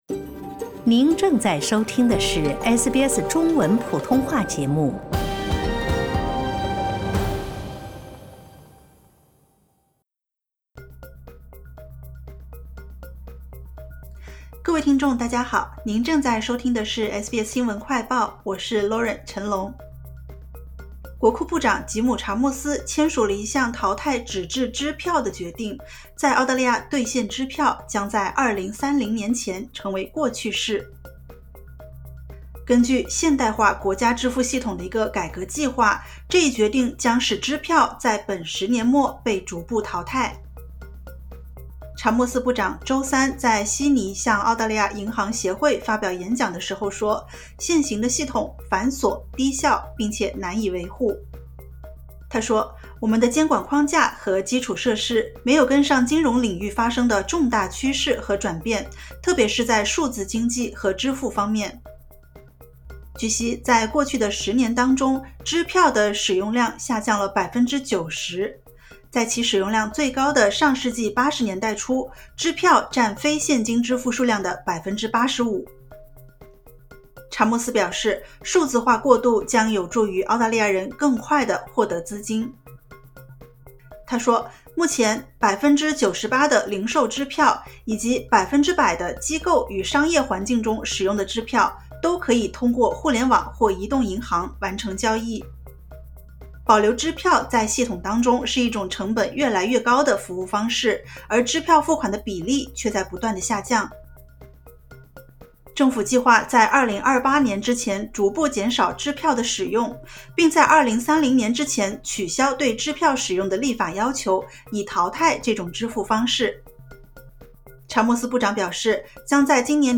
【SBS新闻快报】现代化支付改革：澳大利亚十年内将逐步淘汰支票